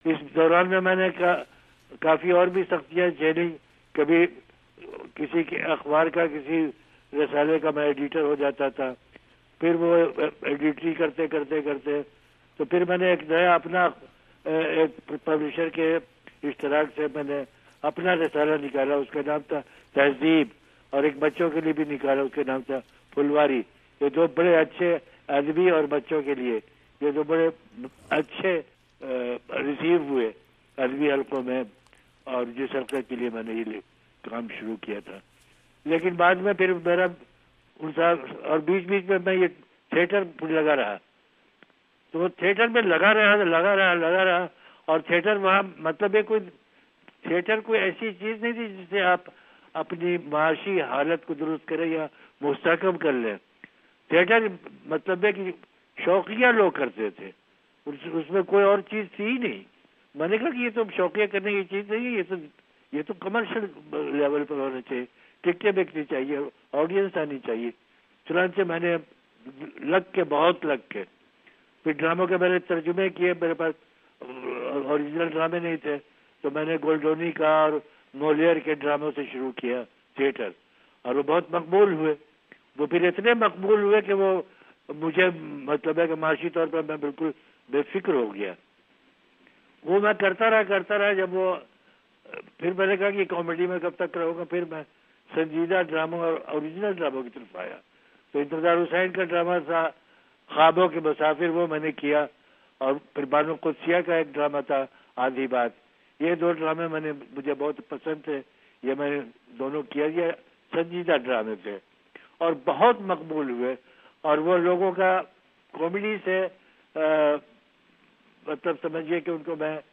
ریڈیو انٹرویو کمال احمد رضوی